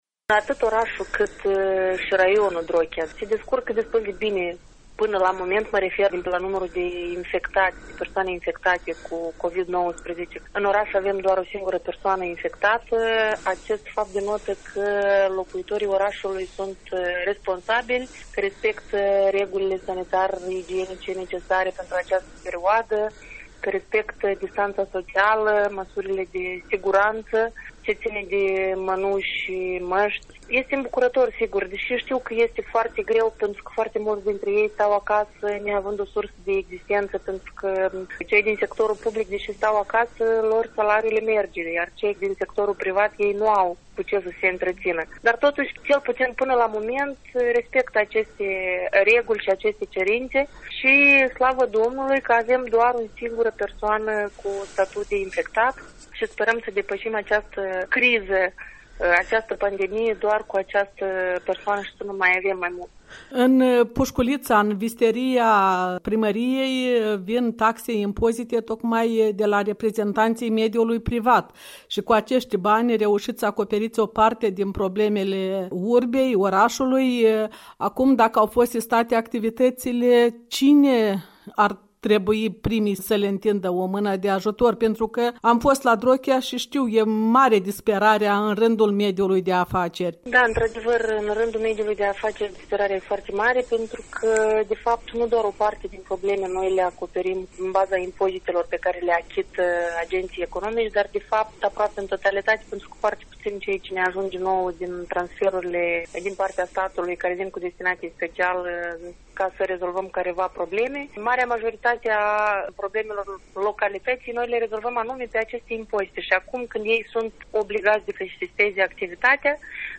Interviu cu Nina Cereteu, primarul orașului Drochia